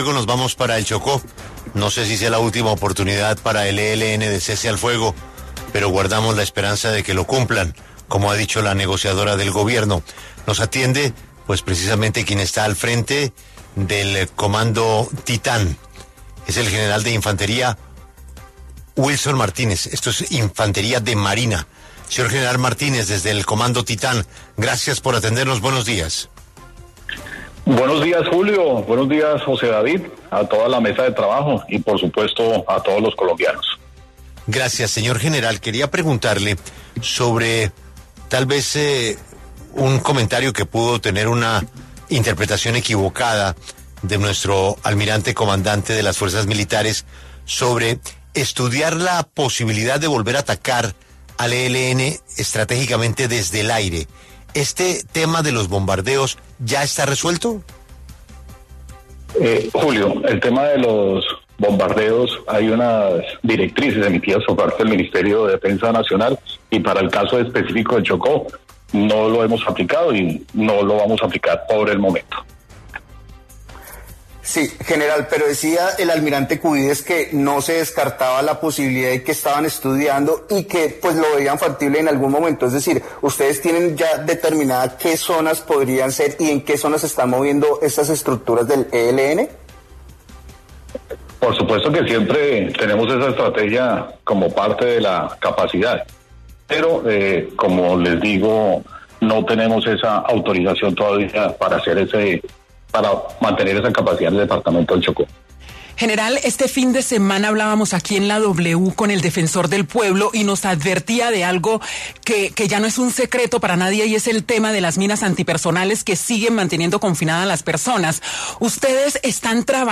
Así lo dijo en diálogo con la W, el general Wilson Martínez, Comandante de la Fuerzas de Tarea Titán que lidera la ofensiva contra el ELN y Clan del Golfo